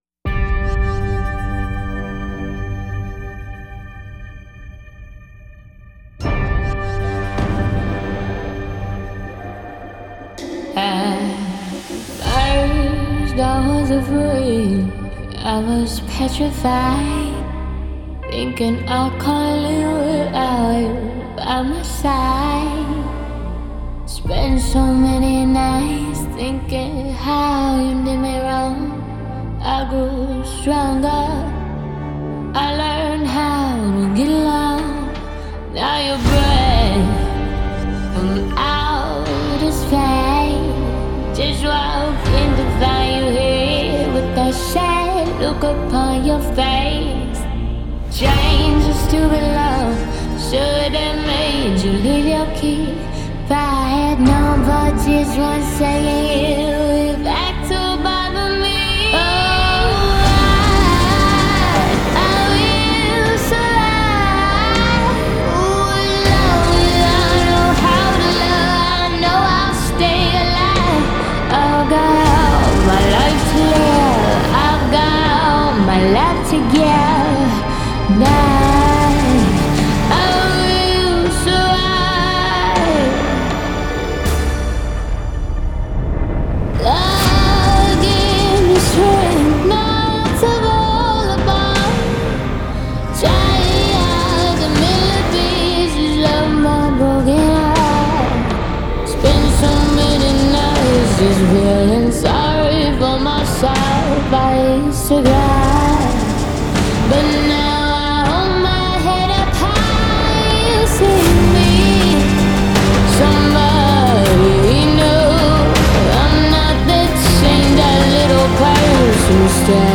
in a style best suited for apocalyptic action movie previews